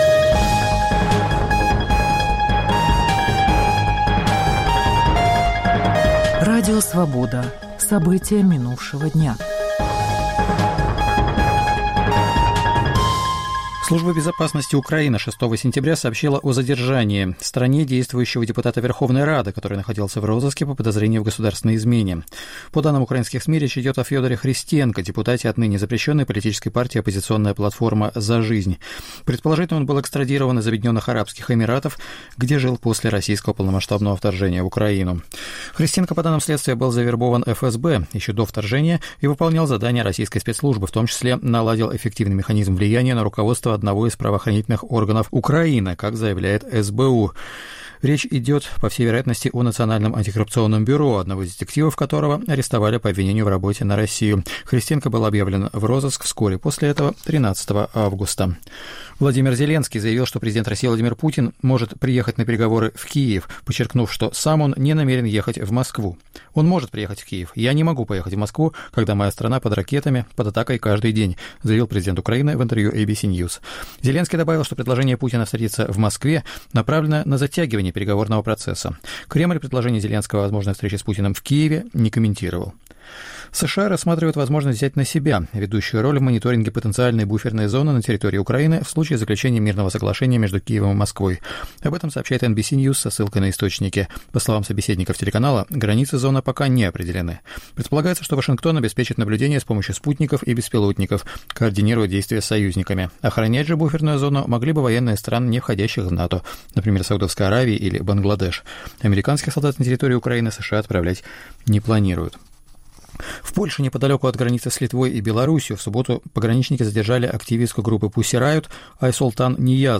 Новости Радио Свобода: итоговый выпуск